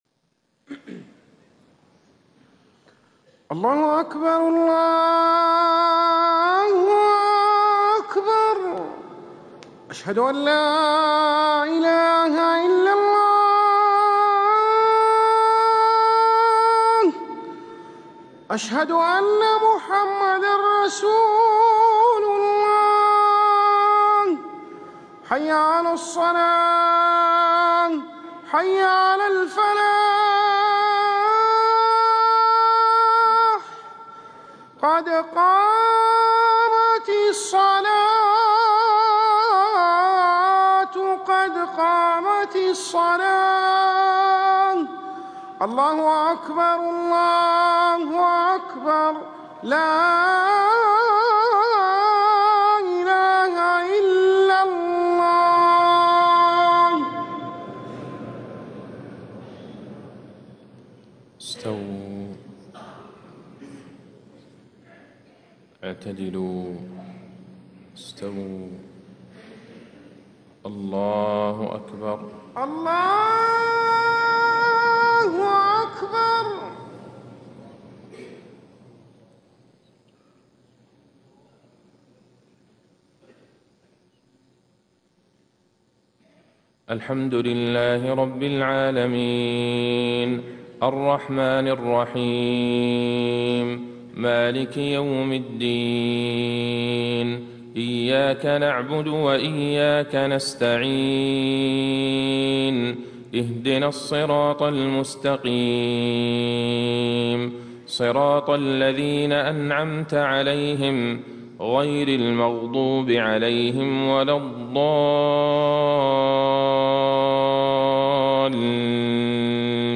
صلاة الفجر من 1 ربيع الاخر 1437هـ خواتيم سورة آل عمران 185-200  > 1437 🕌 > الفروض - تلاوات الحرمين